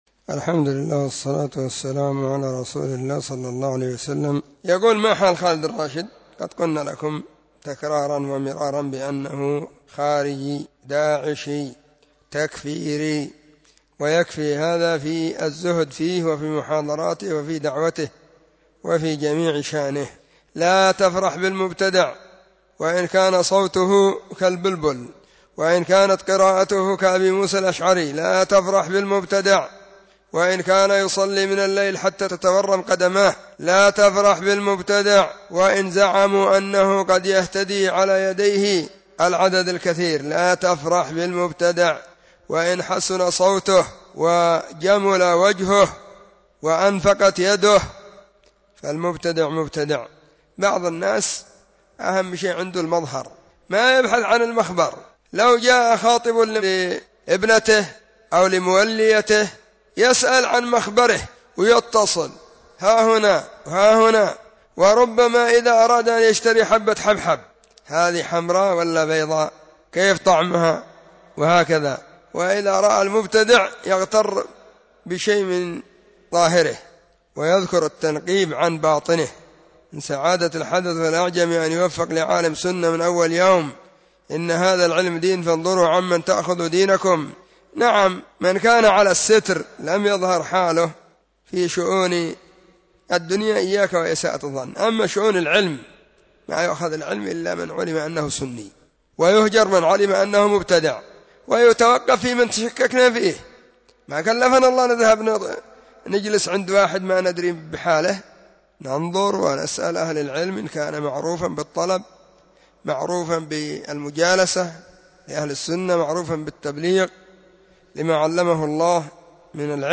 🔸🔹 سلسلة الفتاوى الصوتية المفردة 🔸🔹
📢 مسجد الصحابة – بالغيضة – المهرة، اليمن حرسها الله.